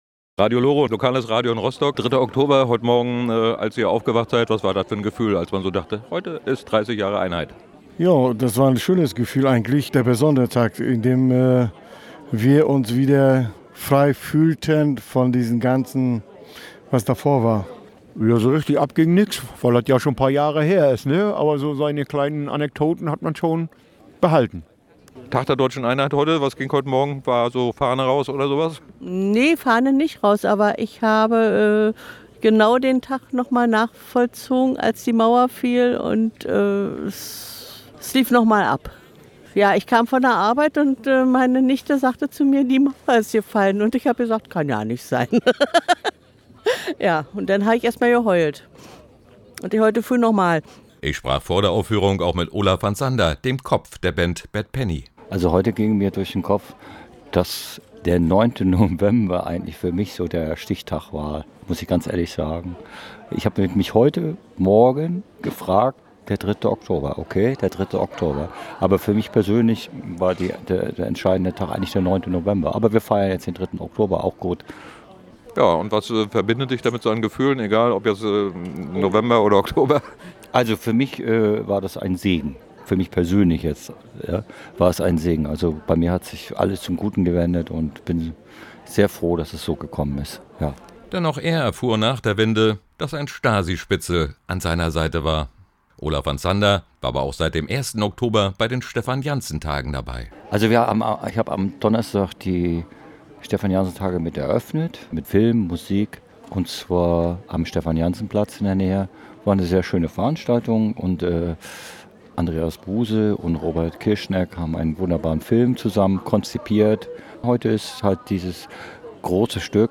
In Warnemünde wurde genau am 3. Oktober auch vieles davon auf die Bühne des Kurhausgartens gebracht. Dort fand die multimediale Aufführung „Dreißig-Die RostRock-Suite III“ statt, die von der Geschichte zweier Brüder handelt.